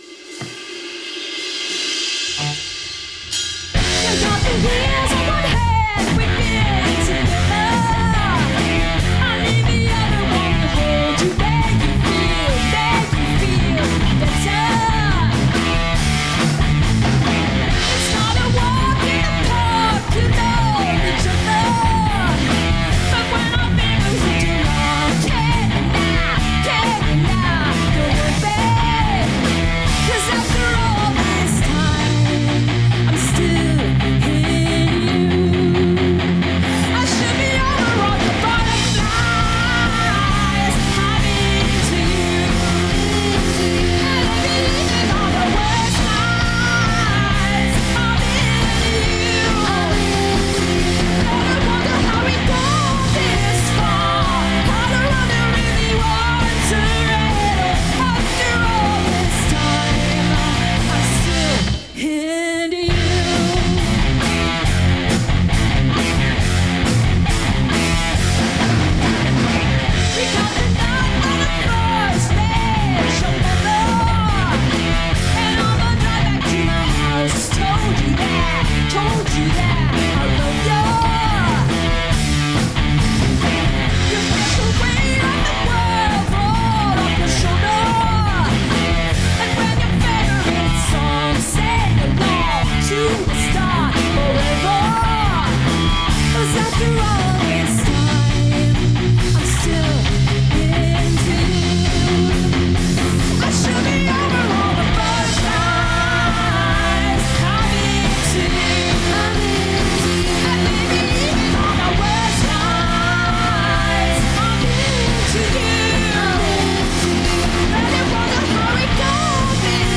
party rock band